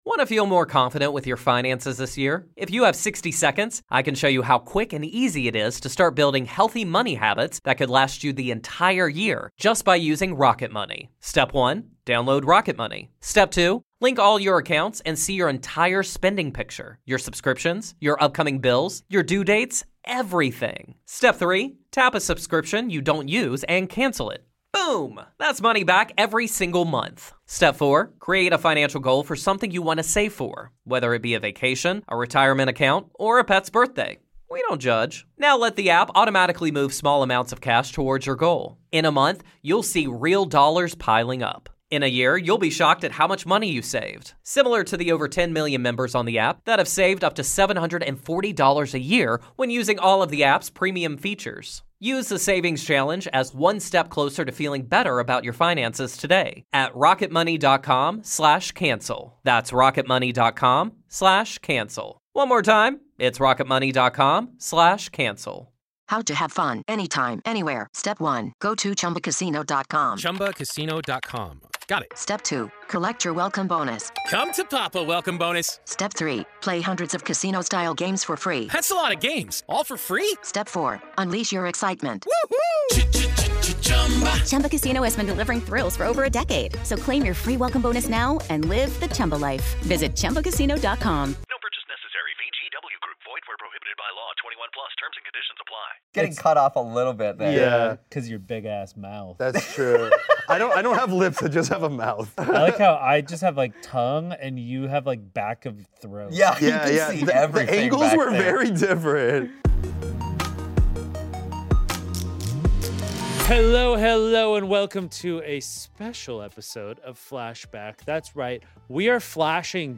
The iconic Annoying Orange creator, Dane Boe, is here to talk to Ian and Anthony about their YouTube journey!